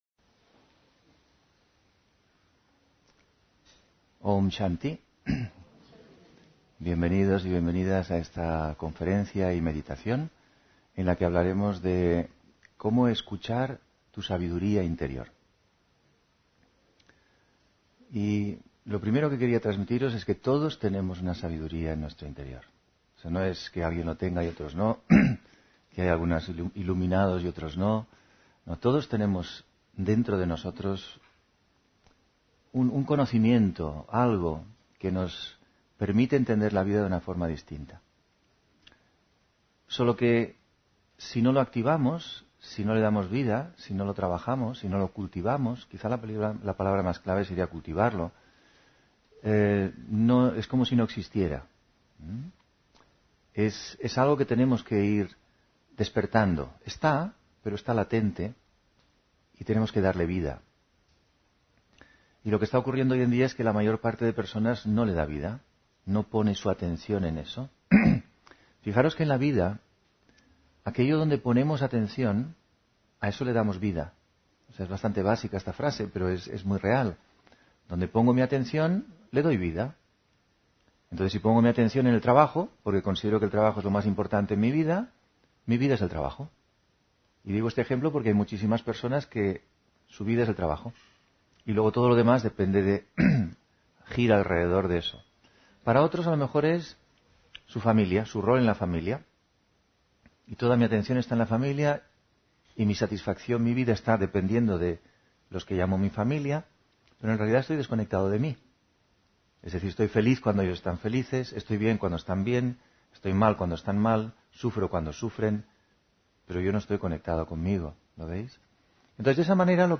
Meditación y conferencia: Escucha tu sabiduría interior (26 Abril 2024)